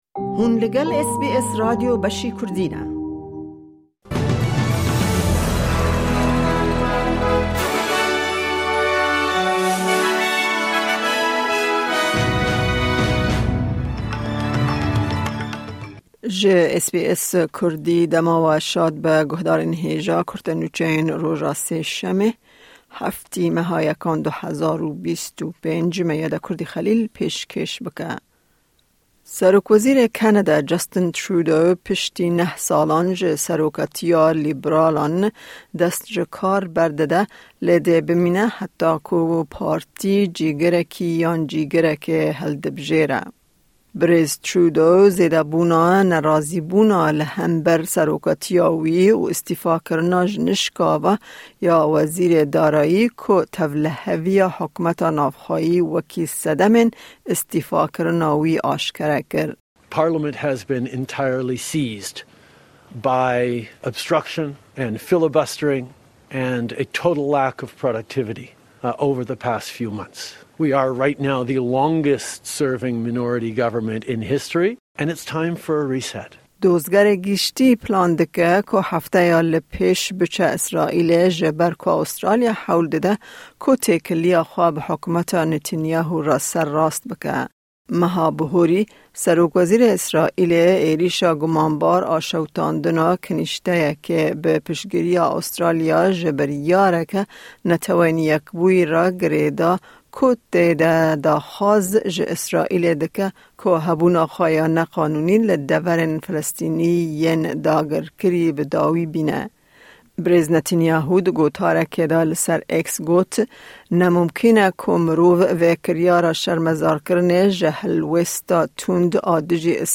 Kurte Nûçeyên roja Sêşemê, 7î Çileya 2025